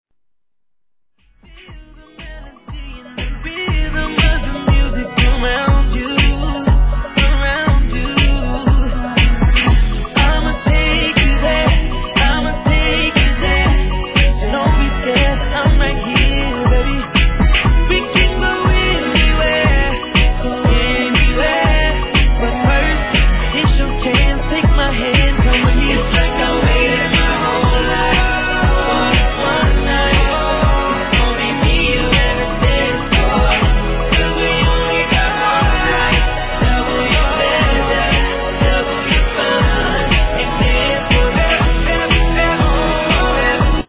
HIP HOP/R&B
(BPM119)